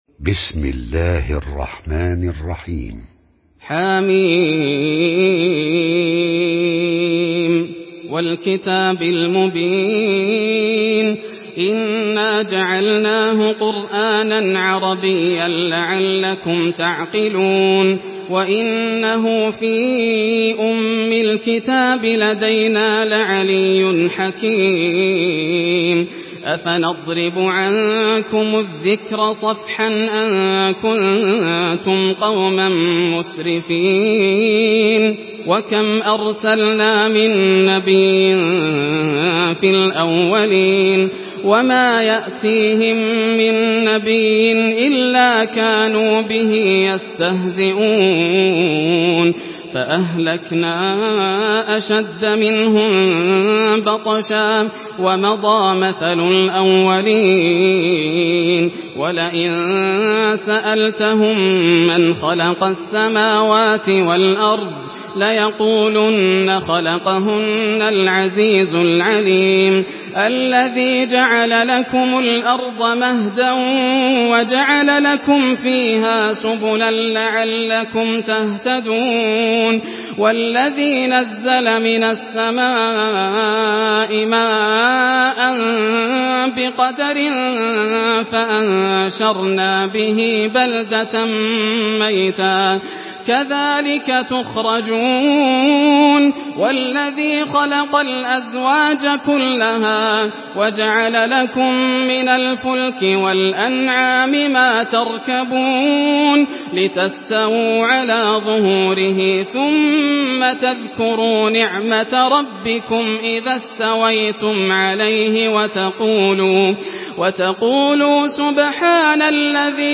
سورة الزخرف MP3 بصوت ياسر الدوسري برواية حفص
مرتل حفص عن عاصم